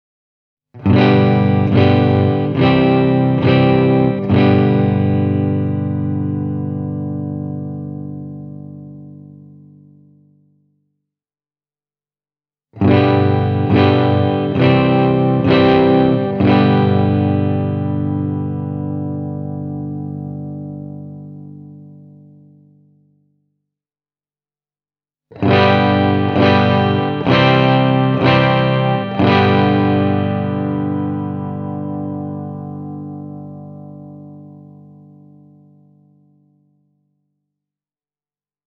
Vaikka tämän kitaran ulkomuoto on 60 vuotta vanha, vaikuttavat mallin aktiiviset mikrofonit siihen, että Edwardsin soundi on nykyaikainen. Duncanin Blackouts-humbuckerit tarjoavat runsaasti lähtötehoa ja selkeyttä, eikä ne ulise runsaalla gainella, mikä tekee niistä hyvän valinnan nyky-Metallille.